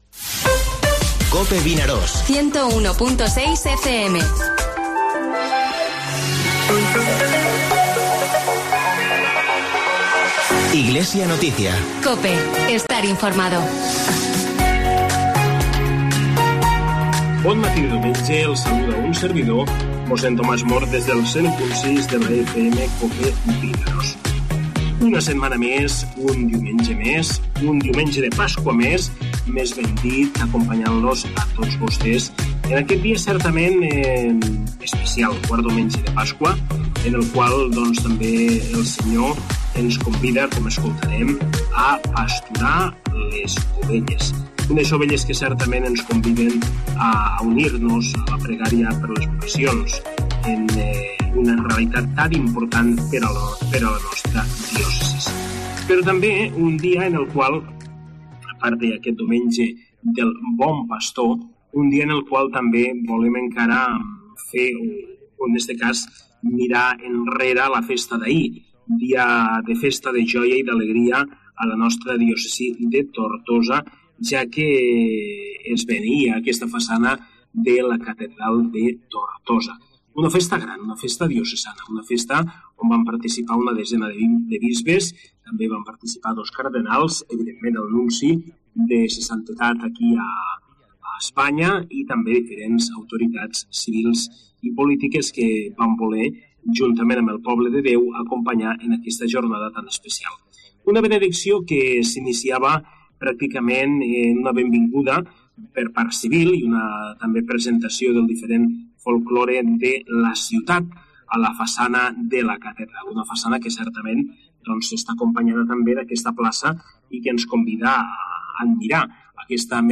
AUDIO: Espai informatiu del Bisbat de Tortosa, tots els diumenges de 9:45 a 10 hores.